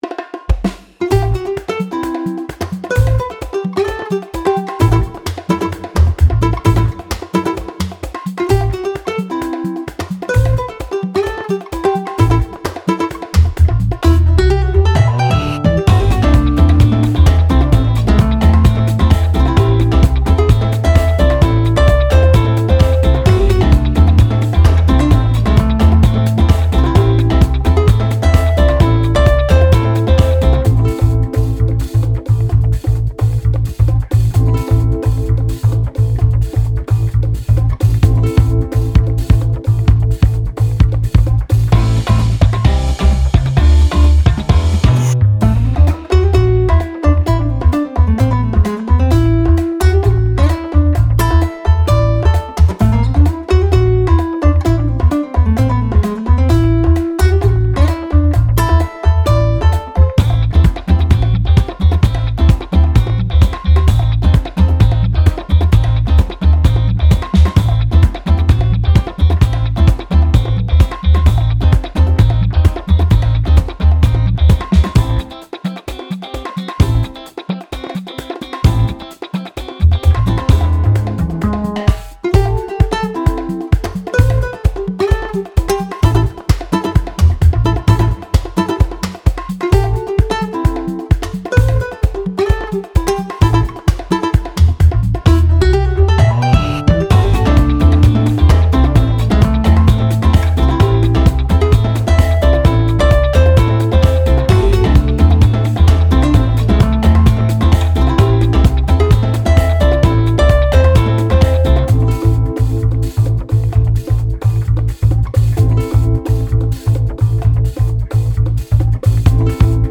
クラシックギターが軽やかに奏でる熱いBGM
アコースティック 4:05 ダウンロード